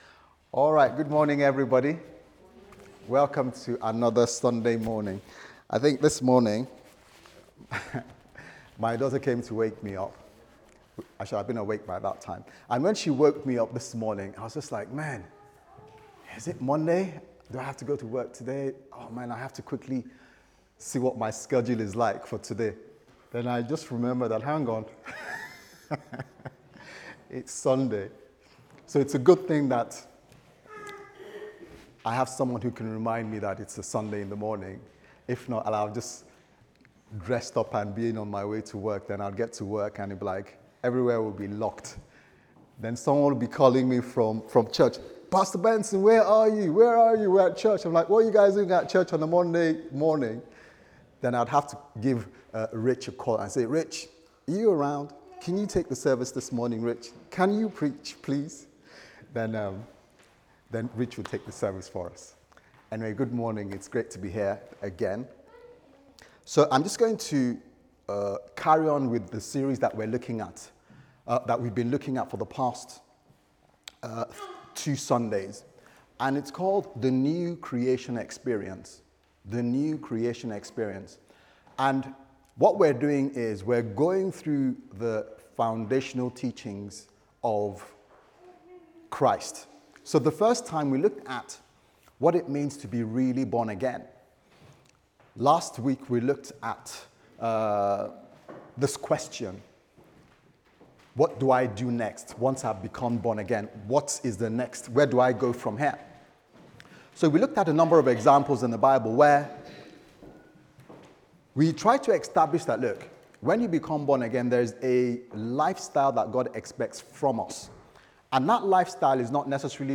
Sunday Service Sermon « The New Creation Experience